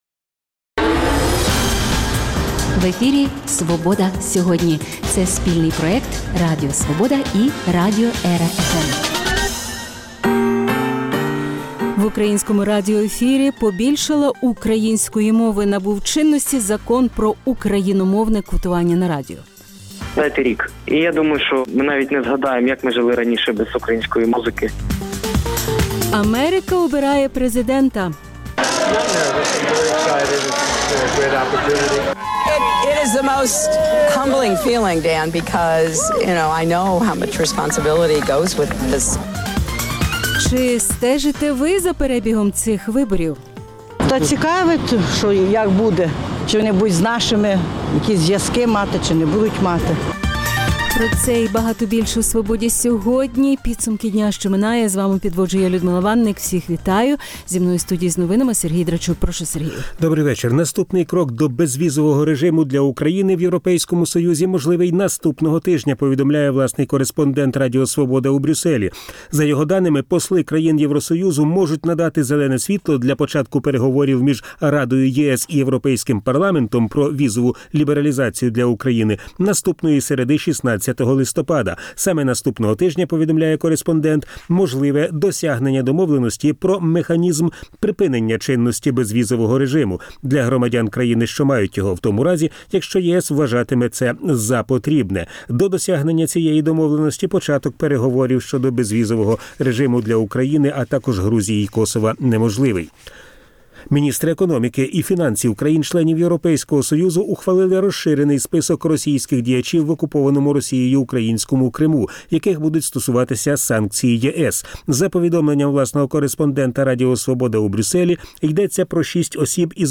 Чи стала Україна заручницею внутрішньоєвропейських криз? А в рубриці до 40 річчя Української Гельсинської Групи почуємо, як бійці з передової читають вірші Василя Стуса